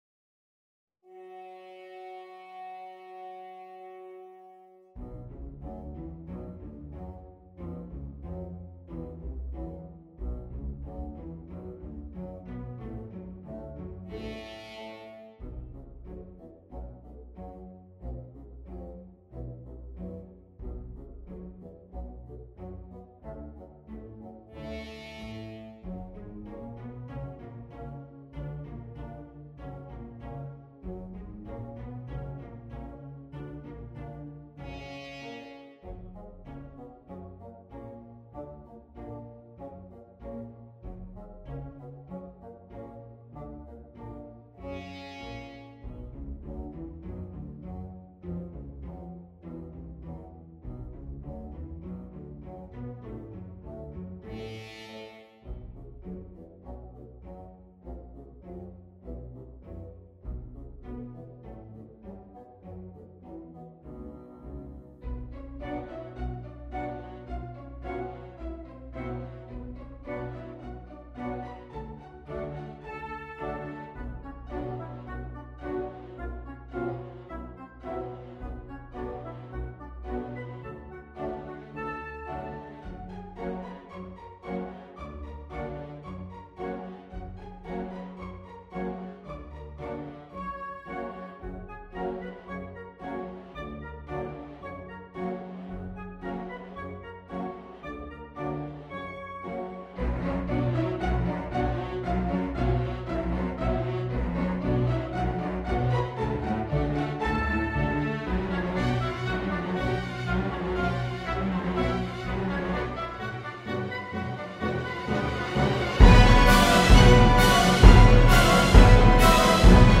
Strings (Violin 1, Violin 2, Viola, Cello, Double Bass)
Orchestra